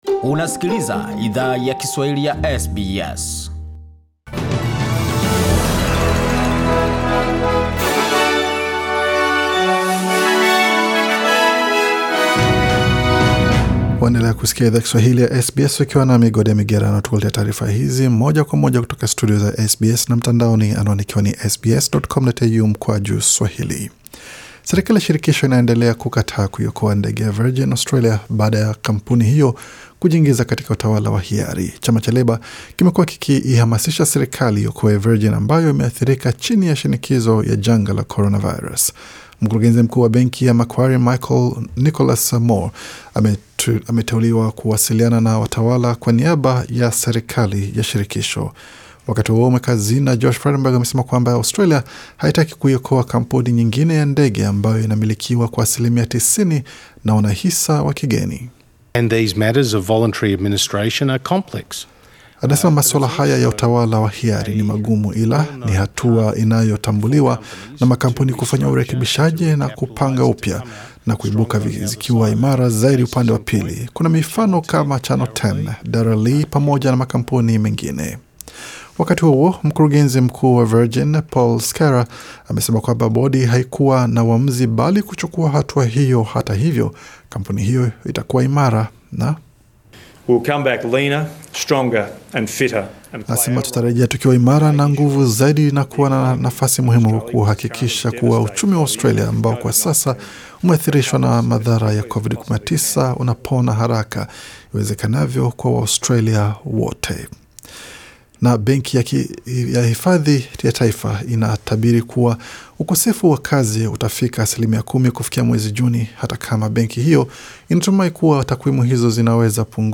Taarifa za habari:Asilimia 10 yawatu kukosa kazi nchini Australia kufikia Juni 2020